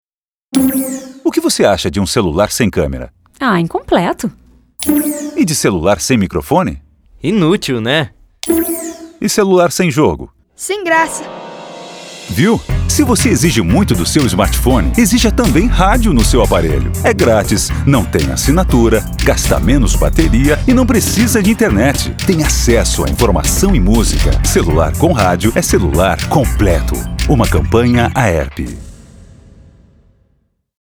Os spots informativos consolidam ainda mais a presença do rádio no celular de graça, como um direito de todos. O Projeto Radiophone tem o objetivo de desmistificar a ideia de que é necessário consumir internet para ouvir rádio no smartphone.